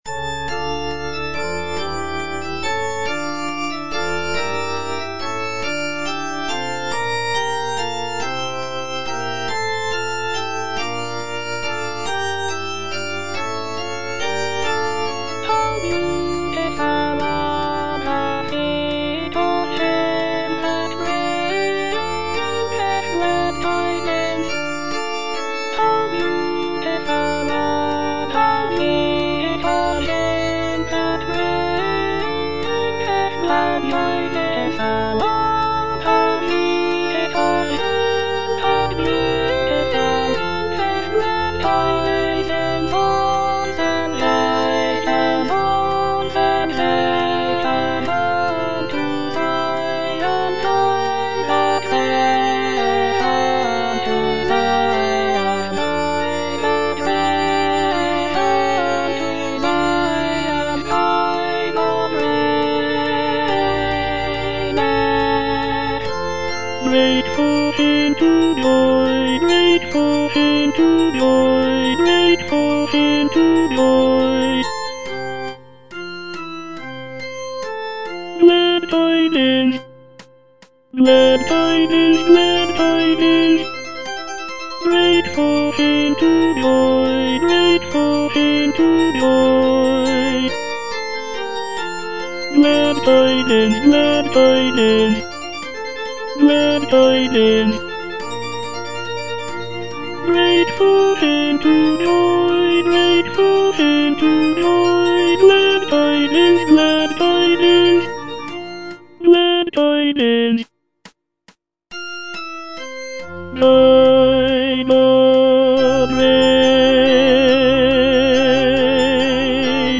G.F. HÄNDEL - HOW BEAUTIFUL ARE THE FEET OF HIM FROM "MESSIAH" (DUBLIN 1742 VERSION) Tenor (Voice with metronome, organ) Ads stop: